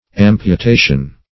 Amputation \Am`pu*ta"tion\, n. [L. amputatio: cf. F.